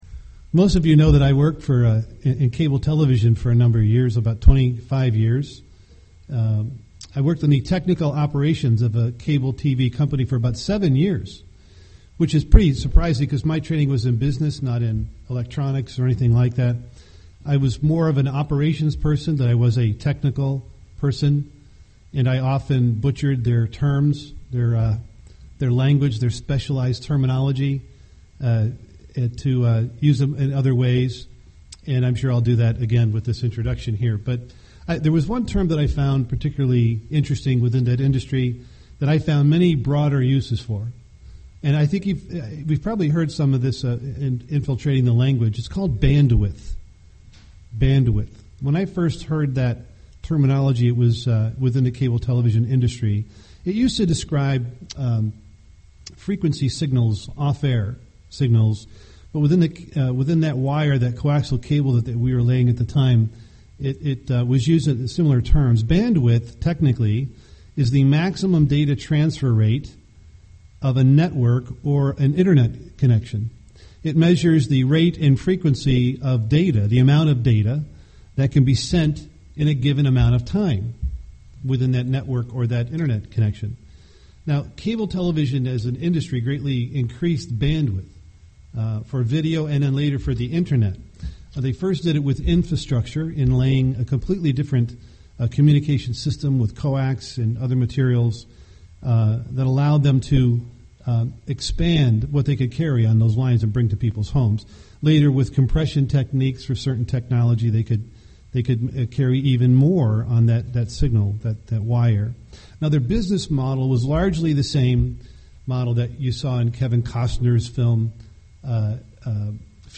UCG Sermon Babel Tower of Babel unity Studying the bible?